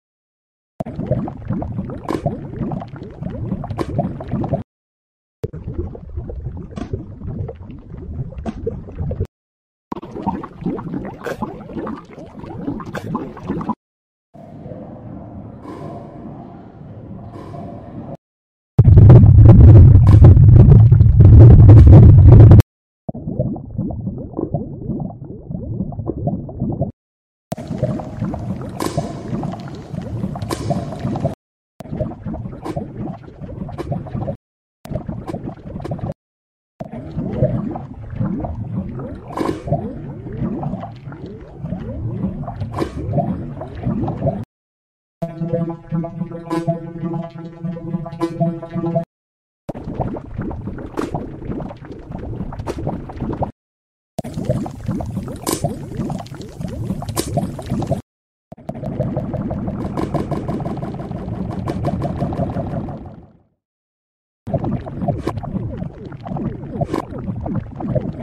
15 Minecraft _Lava_ Sound Variations